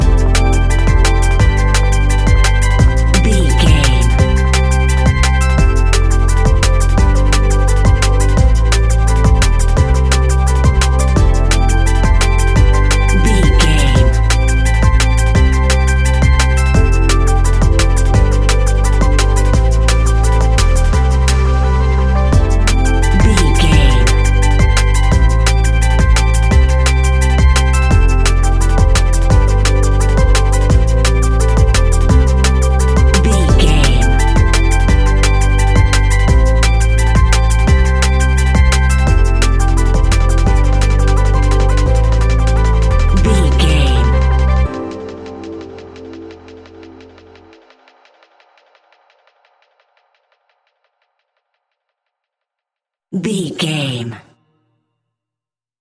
Ionian/Major
electronic
techno
trance
synthesizer
synthwave
instrumentals